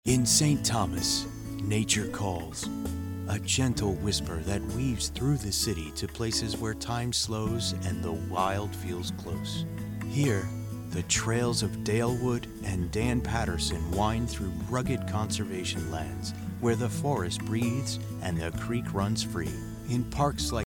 Soft, relaxing delivery of St. Thomas parks, inviting you to see for yourself and unwind!